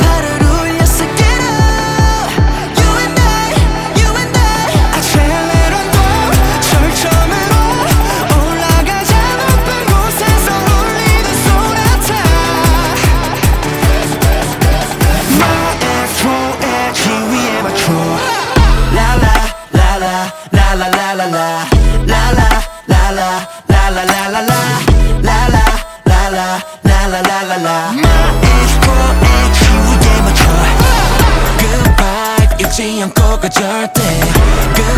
Жанр: Поп / K-pop